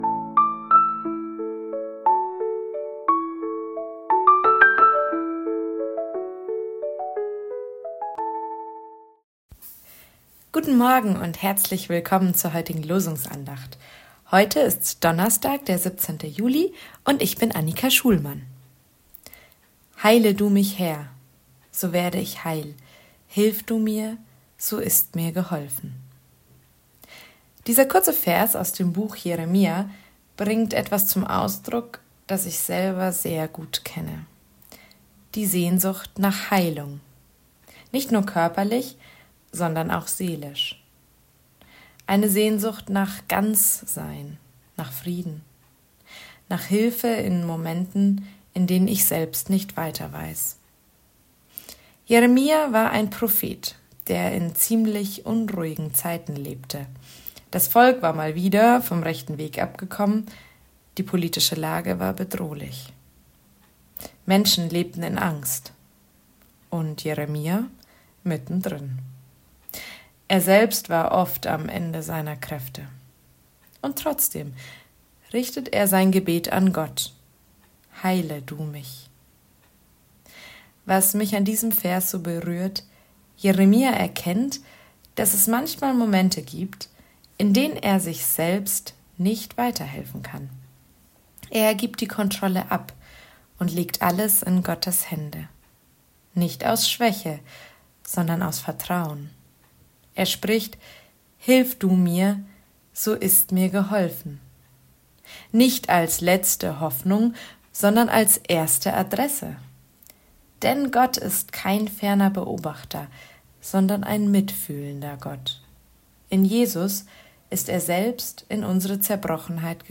Losungsandacht für Donnerstag, 17.07.2025